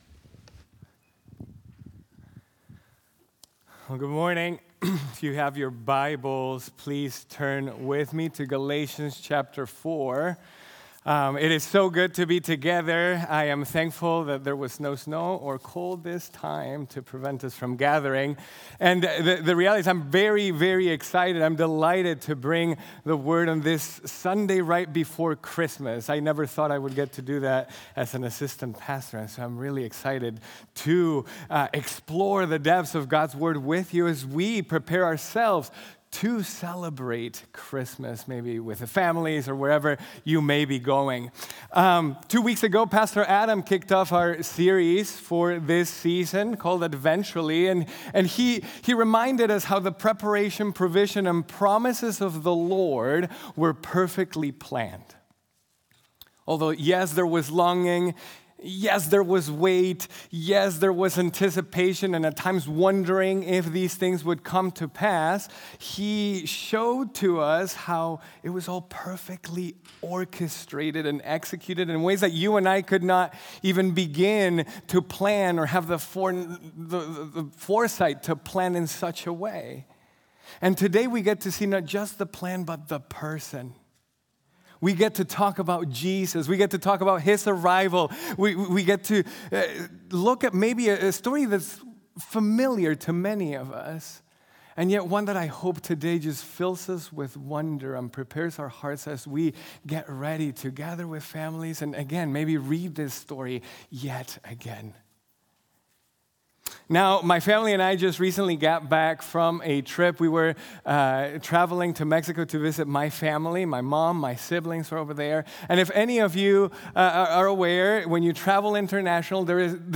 God Sent Forth His Son | Baptist Church in Jamestown, Ohio, dedicated to a spirit of unity, prayer, and spiritual growth